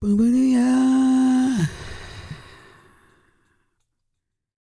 E-CROON 3049.wav